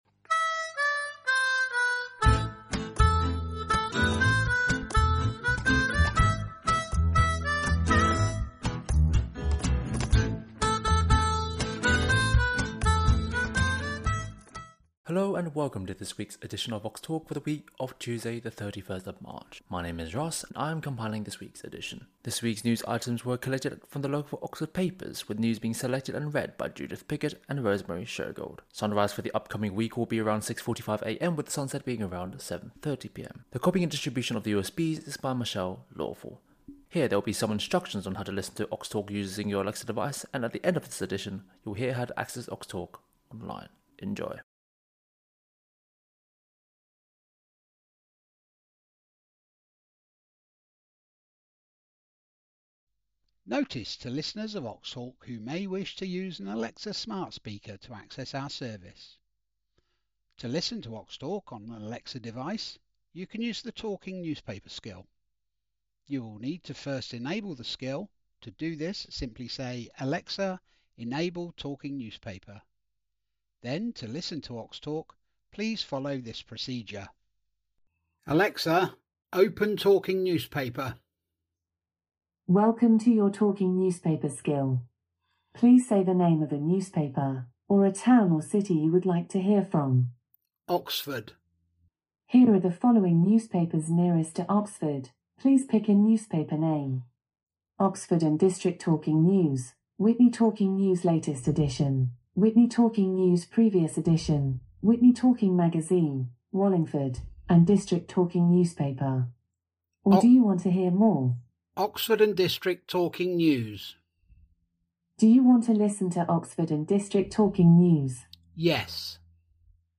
Talking News: 31st March 2026